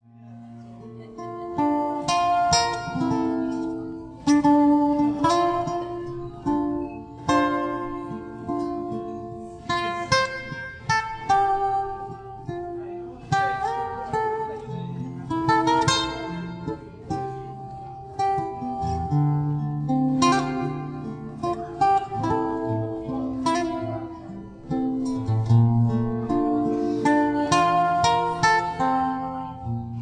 Solo Guitar Standards
Soothing and Relaxing Guitar Music